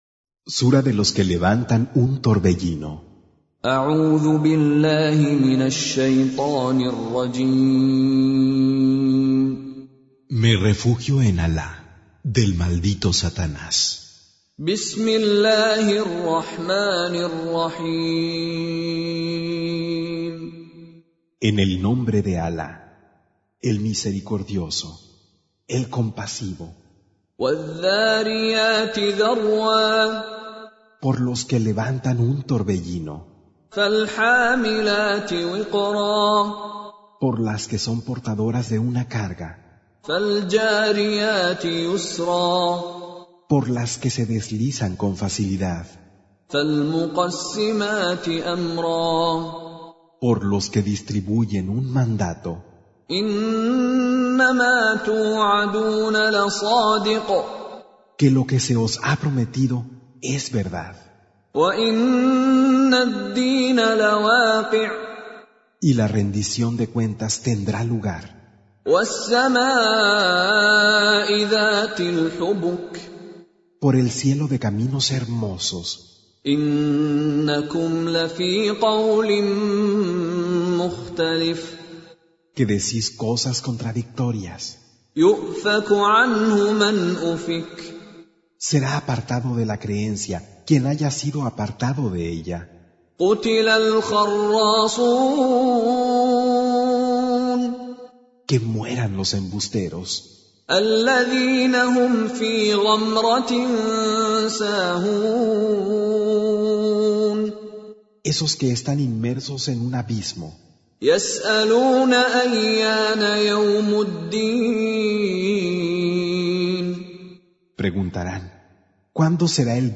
Traducción al español del Sagrado Corán - Con Reciter Mishary Alafasi
Surah Sequence تتابع السورة Download Surah حمّل السورة Reciting Mutarjamah Translation Audio for 51.